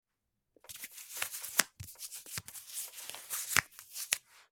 Звуки лифчика
Шорох регулируемых бретелек лифчика